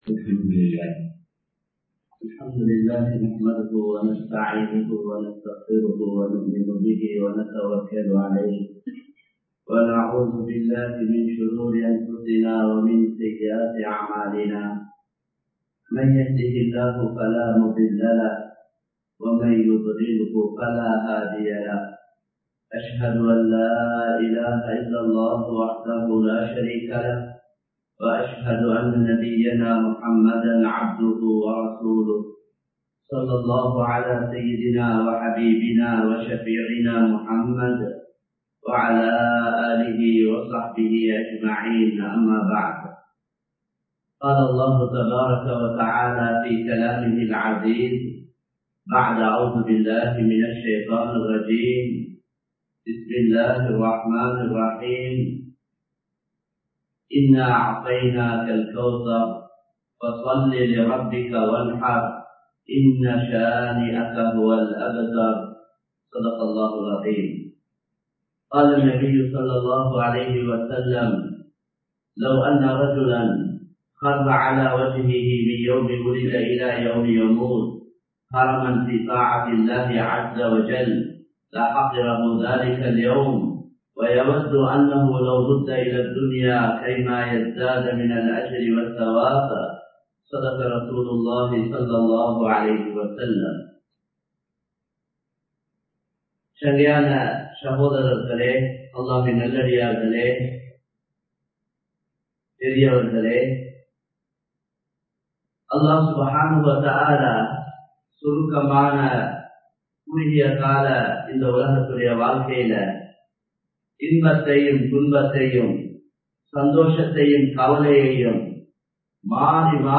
மக்களின் விருப்பத்திற்காக வாழாதீர்கள் (Don't Live for will of people) | Audio Bayans | All Ceylon Muslim Youth Community | Addalaichenai
Muhiyadeen Jumua Masjith